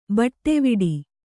♪ baṭṭeviḍi